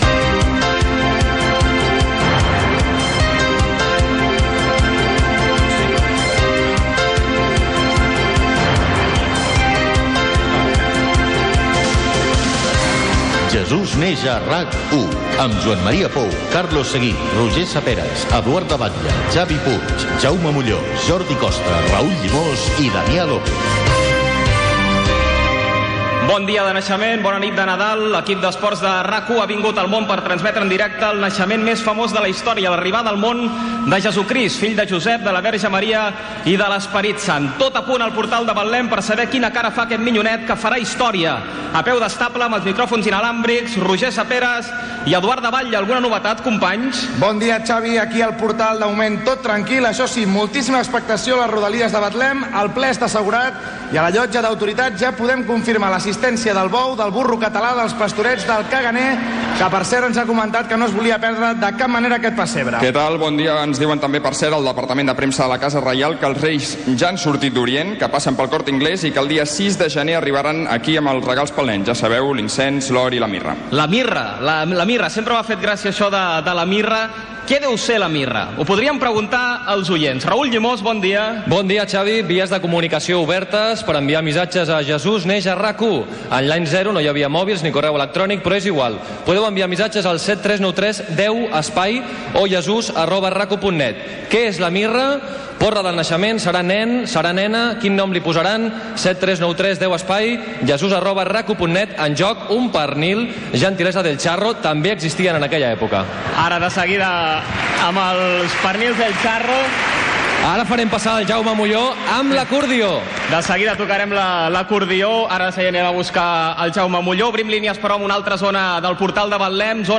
Ficció
Espai fet cara al públic al Palau de la Música Catalana